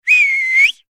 Whistle.ogg